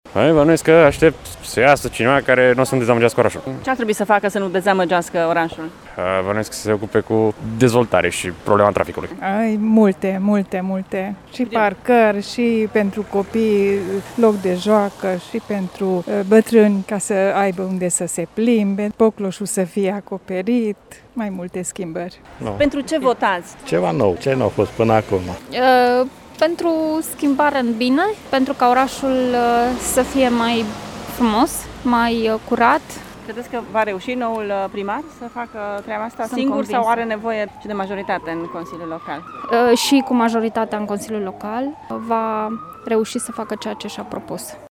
Este gândul cu care s-au prezentat la urne în această dimineață unii târgumureșeni care au stat minute în șir în ploaie, la rând, pentru a putea intra în secția de vot.
Oamenii speră că noua administrație va schimba în bine fața orașului dar recunosc că asta depinde și de Consiliul Local: